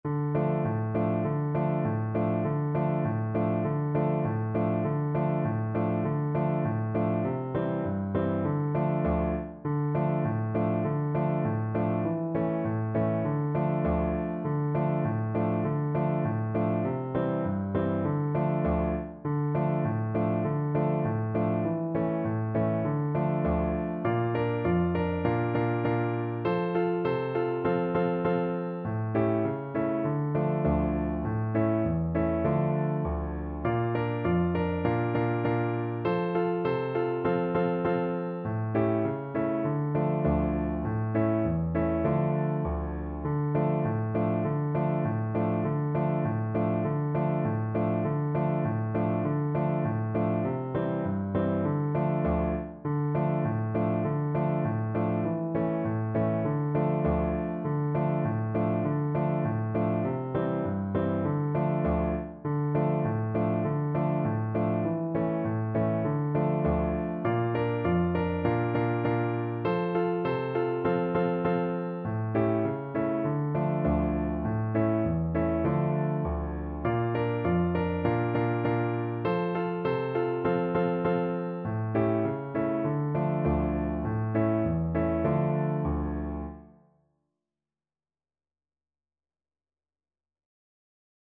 4/4 (View more 4/4 Music)
Bolivian